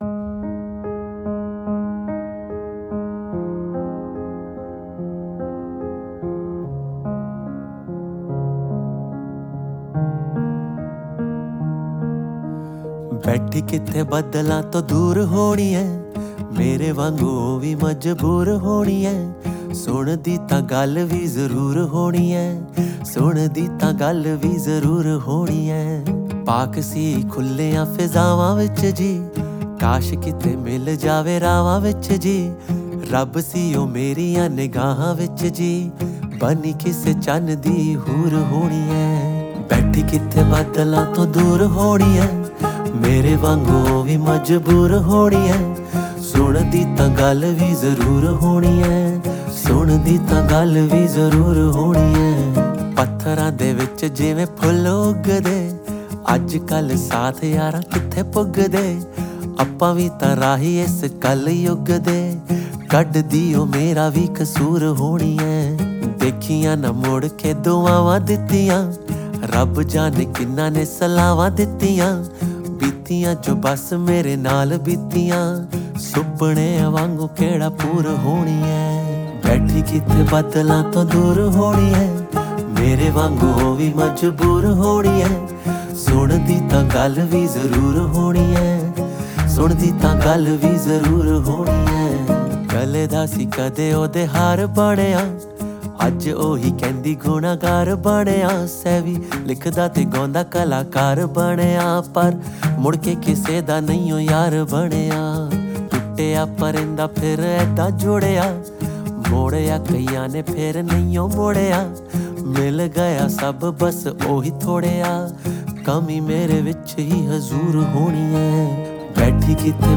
haryanvi songs